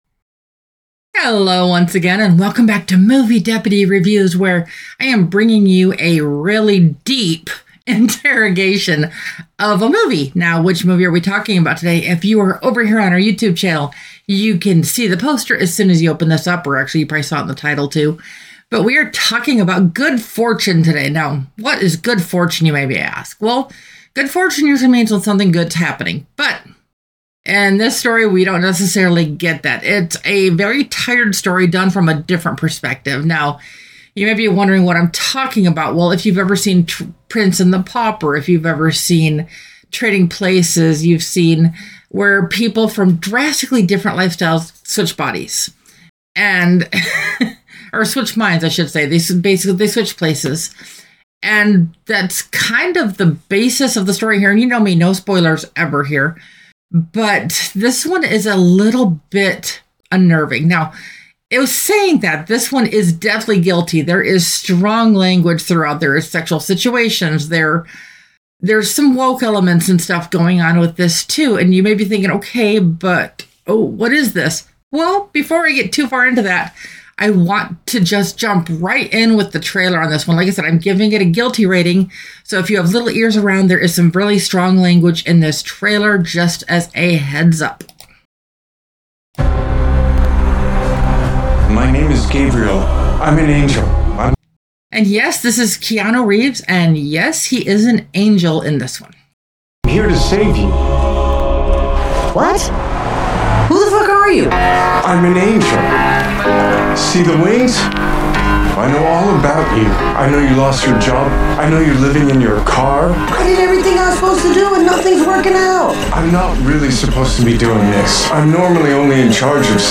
Original Review: (Contains Trailer Audio)